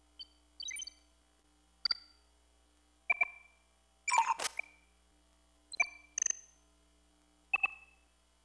command_hum.wav